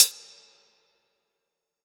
ClosedHH MadFlavor 3.wav